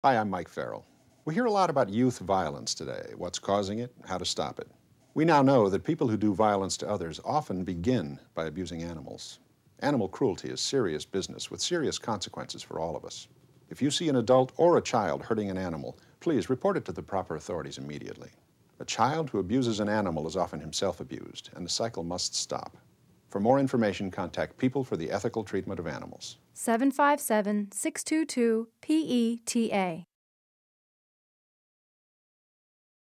Instructions for Downloading This Radio PSA Audio File